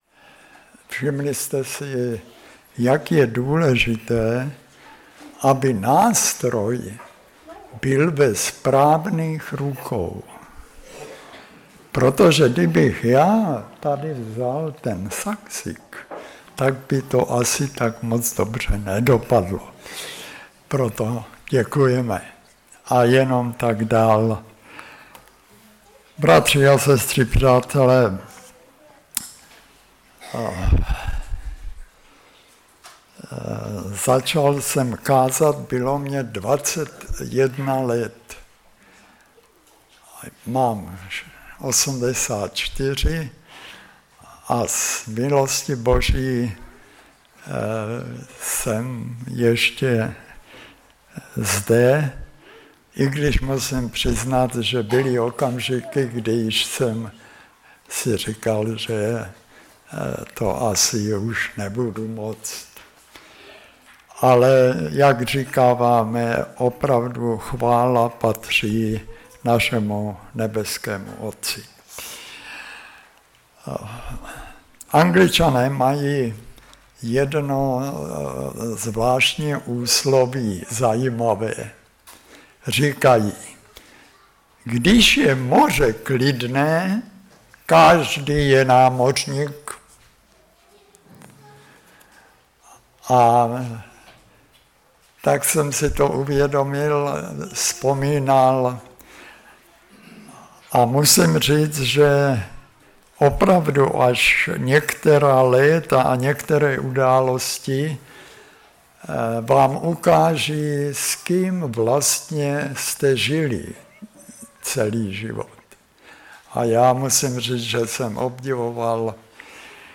Kázání
z technických důvodů zkrácená nahrávka Kazatel